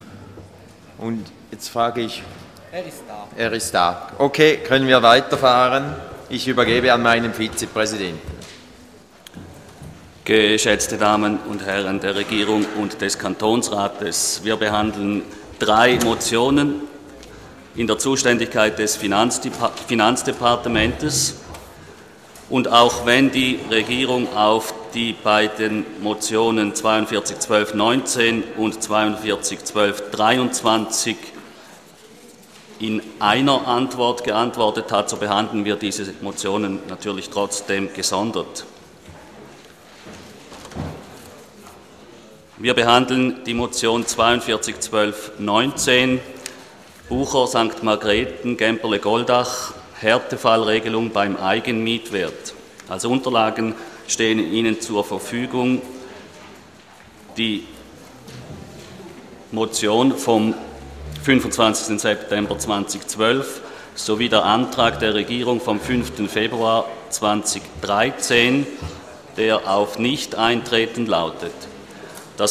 Session des Kantonsrates vom 25. bis 27. Februar 2013